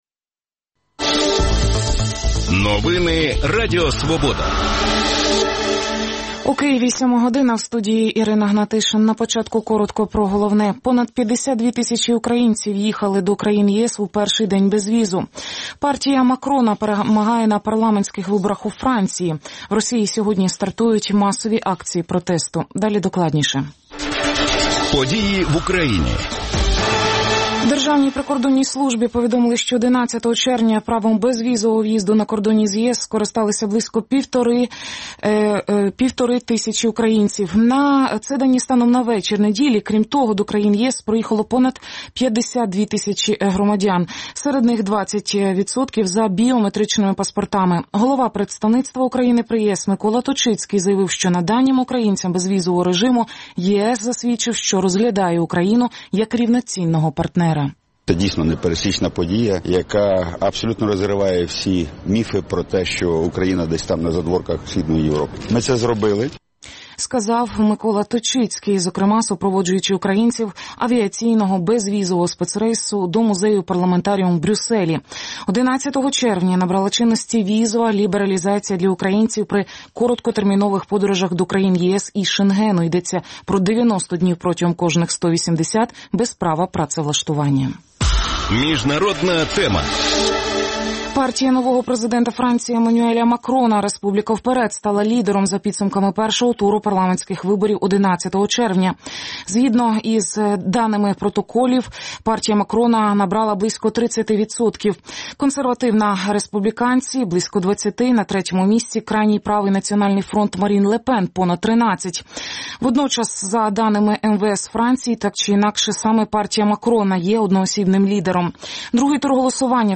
говоритиме з гостями студії.